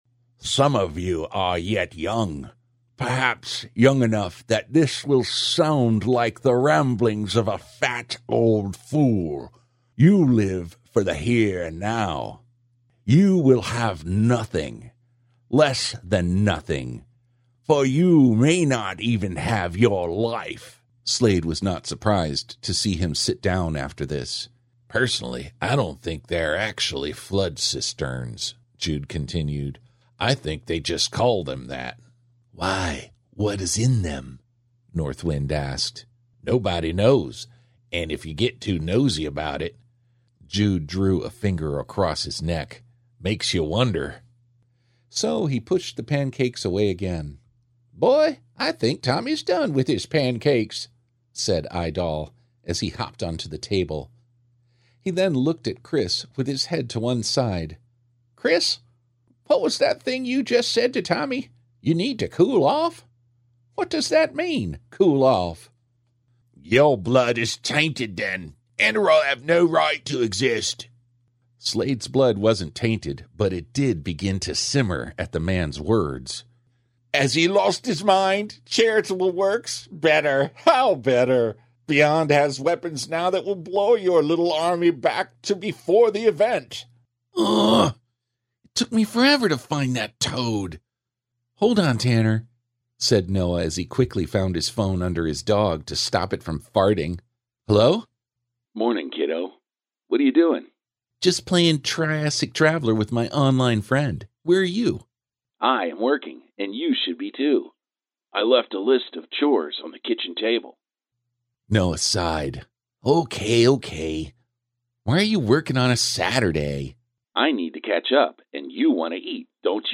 Smooth Voices
Audiobook Character Dialogue and Narration – Thank you for listening!